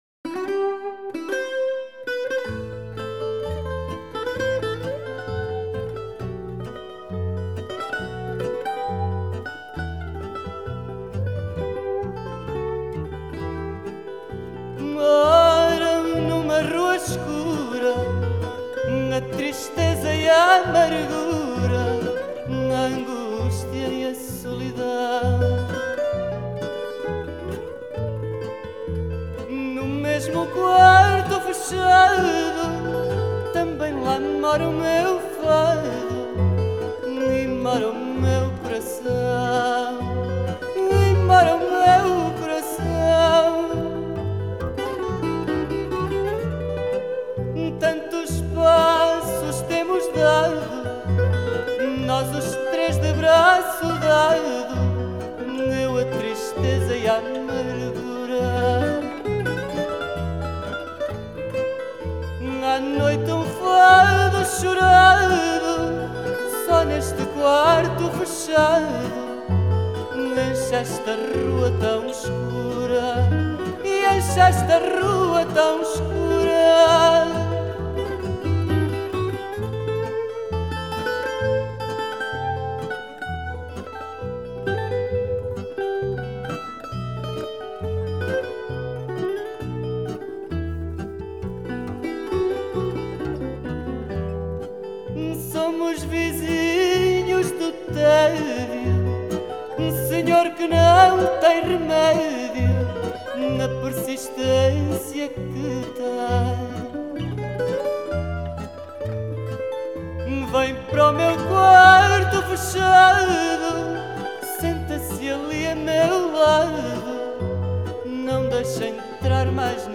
Genre: Folk, World, Fado